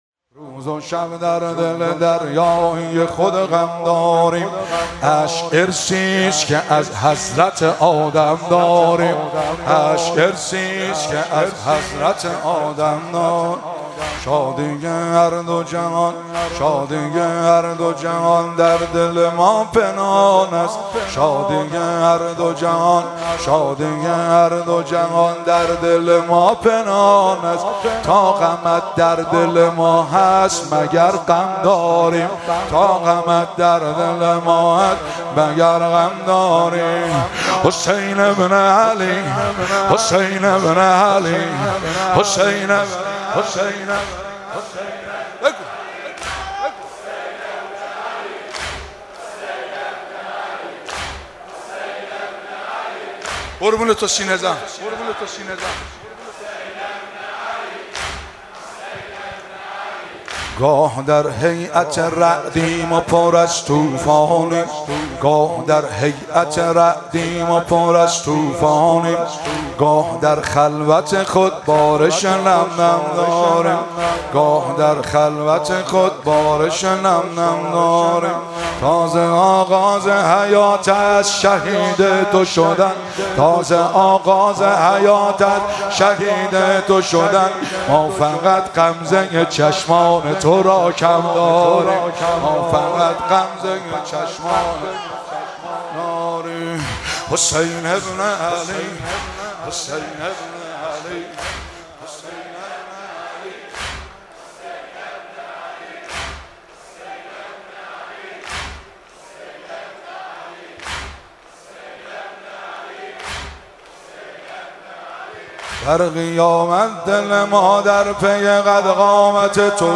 با صدای مداح اهل بیت